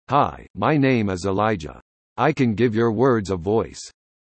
Multiple Voices to Choose From
Male US